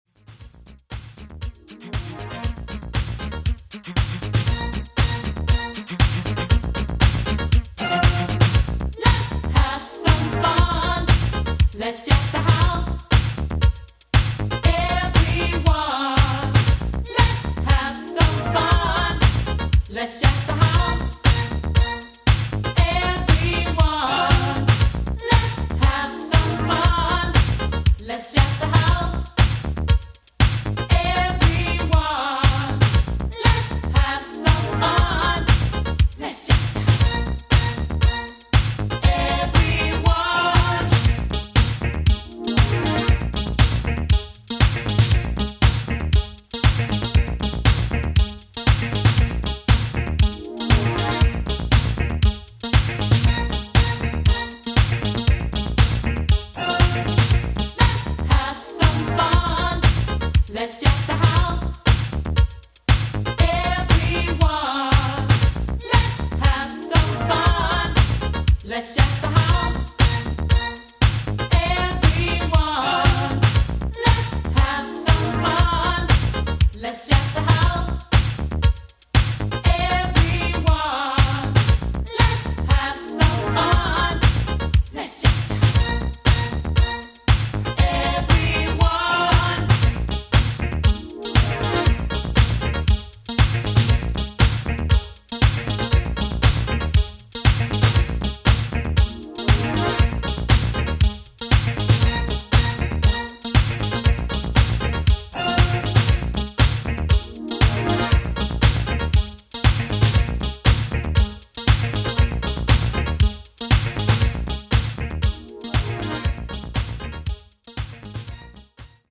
[90SHOUSE]